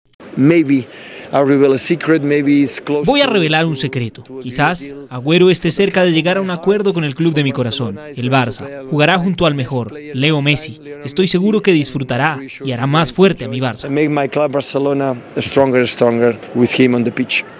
(Pep Guardiola en entrevista con la BBC)